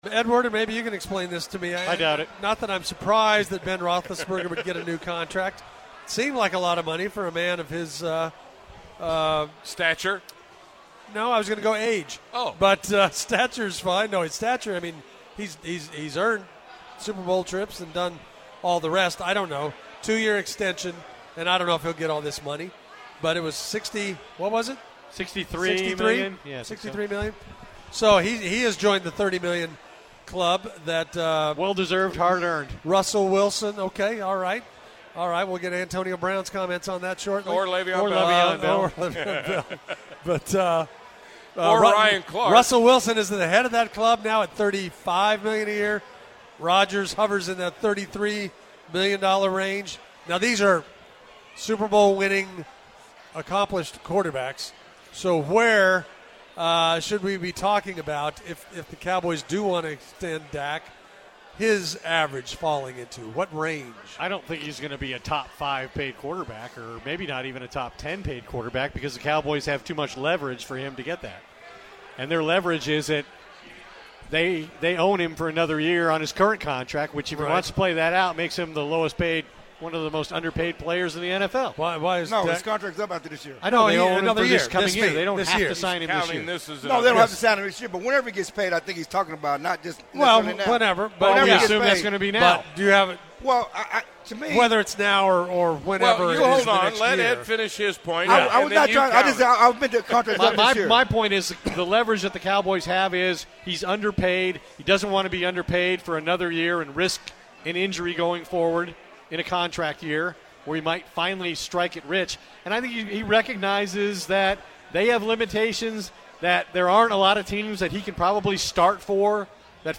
The round table discuss other big contracts around the NFL, and how they affect what Dak will get.